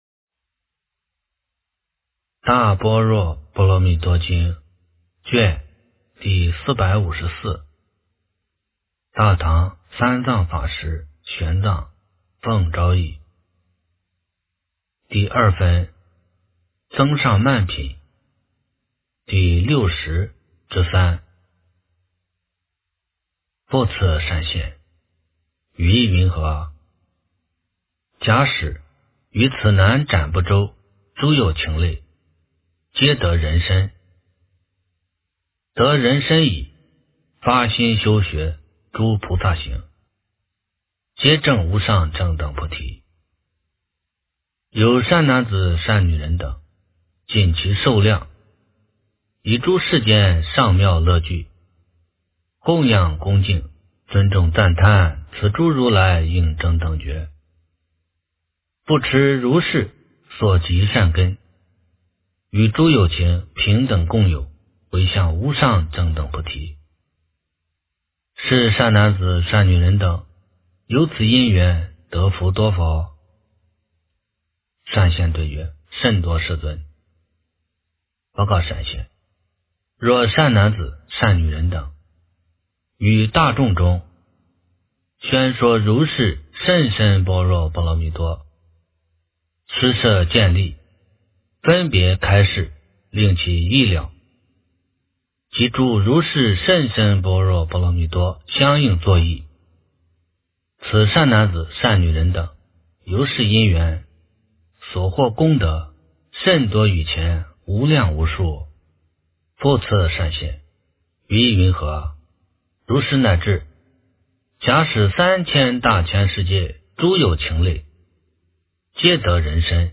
大般若波罗蜜多经第454卷 - 诵经 - 云佛论坛